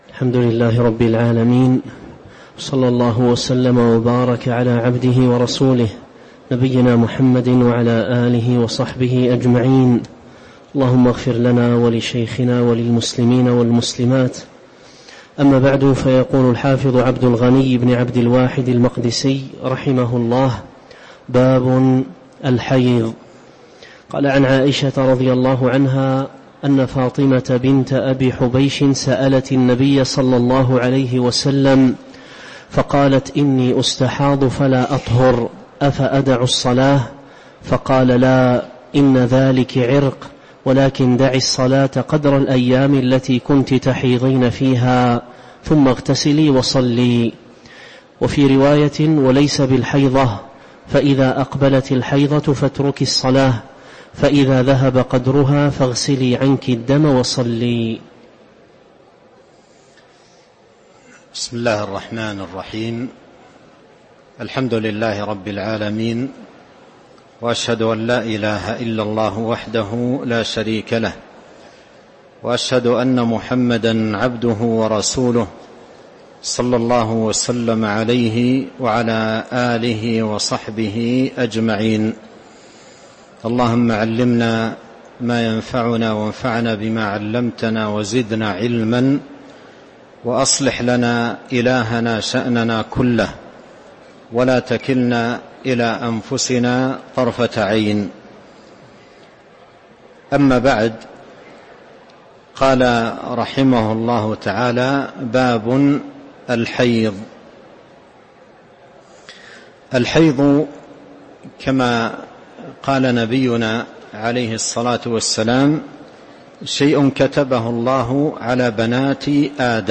تاريخ النشر ١٧ ربيع الأول ١٤٤٤ هـ المكان: المسجد النبوي الشيخ: فضيلة الشيخ عبد الرزاق بن عبد المحسن البدر فضيلة الشيخ عبد الرزاق بن عبد المحسن البدر قوله: باب الحيض (012)